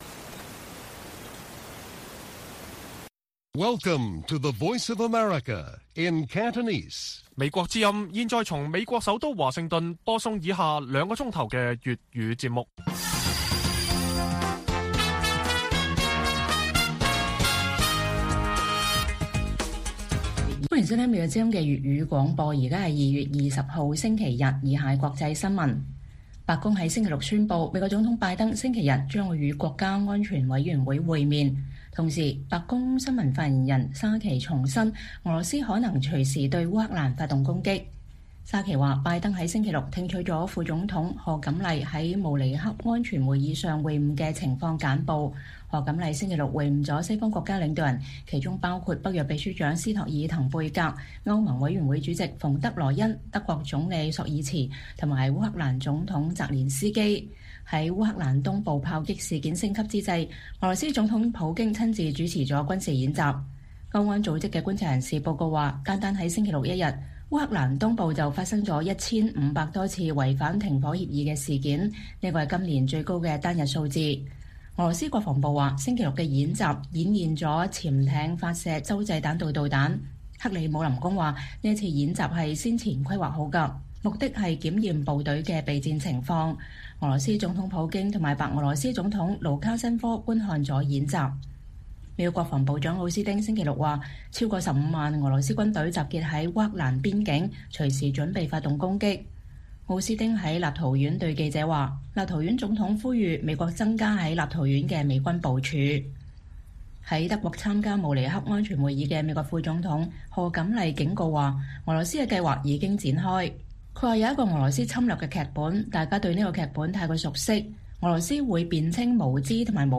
粵語新聞 晚上9-10點：拜登週日就烏克蘭問題與國家安全委員會會面